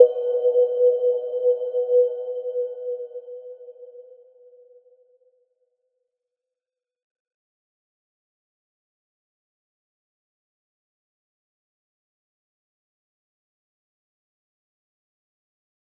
Little-Pluck-B4-mf.wav